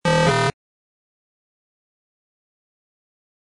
incorrect.wav